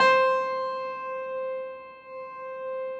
53g-pno14-C3.wav